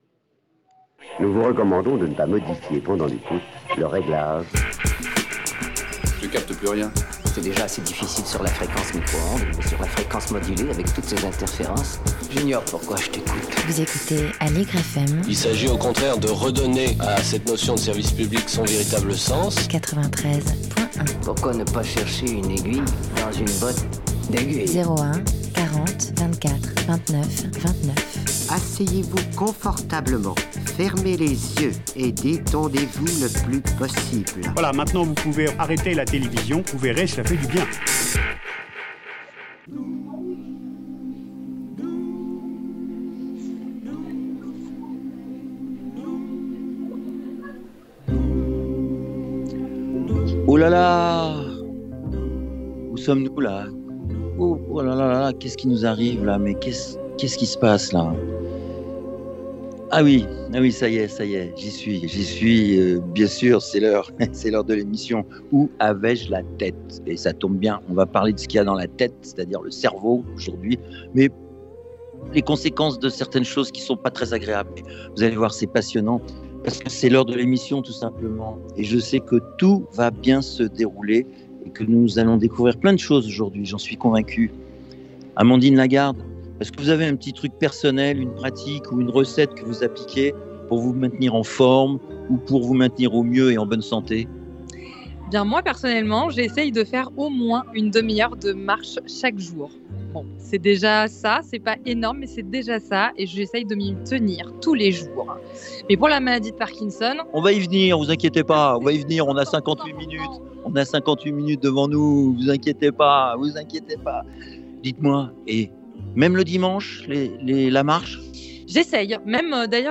Une rencontre au Park'cœur village créé de toutes pièces, place Saint-Sulpice à Paris. Une émission où les invités de L’étincelle dans la ville nous parlent de la maladie de Parkinson.